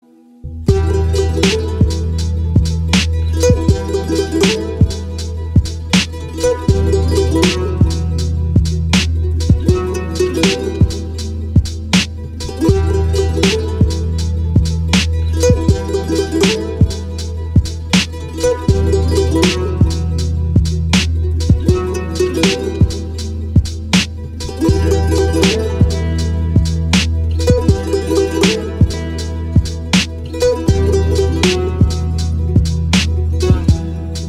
• Качество: 192, Stereo
грустные
атмосферные
спокойные
без слов
Chill Trap
депрессивные
Trip-Hop